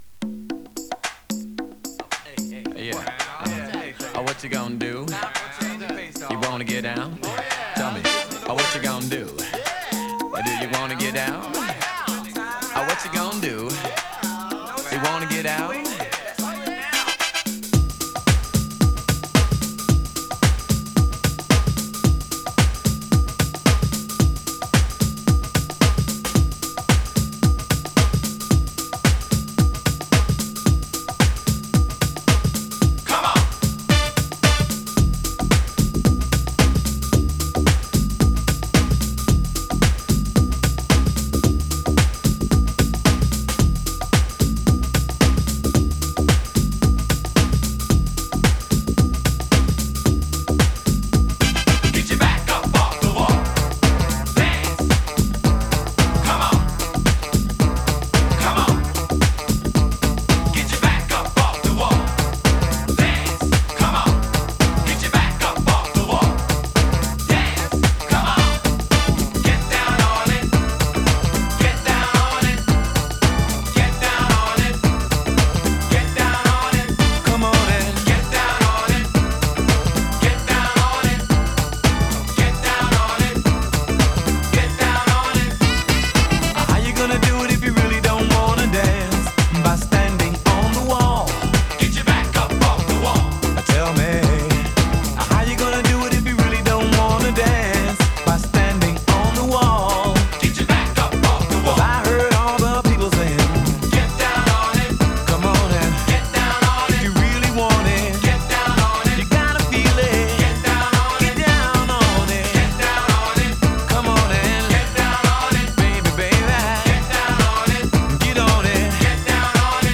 モダンソウル